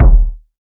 Kicks
KICK.86.NEPT.wav